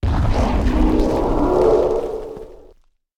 prepare_attack1.ogg